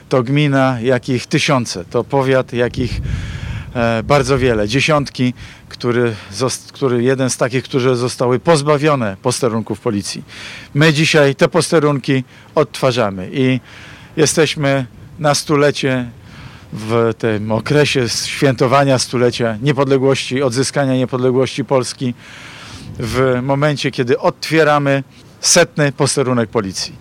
Mateusz Morawiecki, premier Rzeczypospolitej Polskiej wziął w czwartek (28.02.19) udział w otwarciu, reaktywowanego po 19-tu latach, posterunku policji w Dubeninkach.